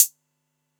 Closed Hats
ClosedHH 888 1.wav